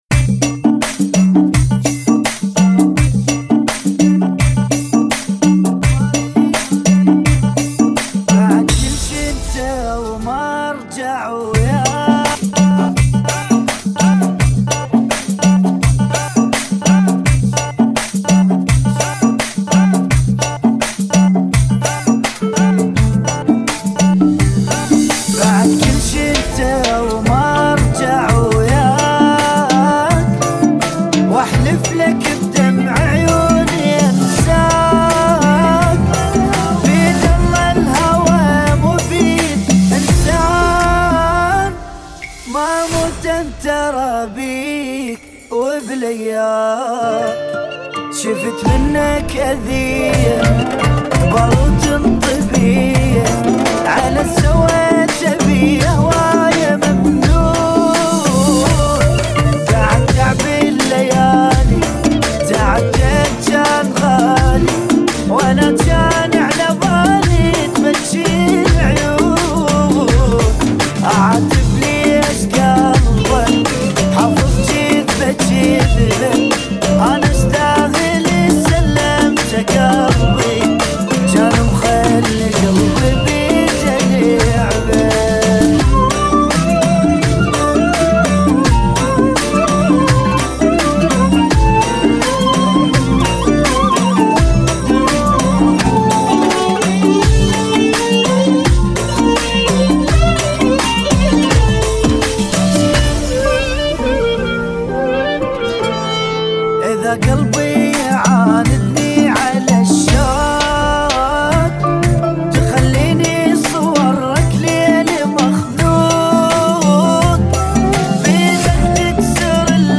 84 bpm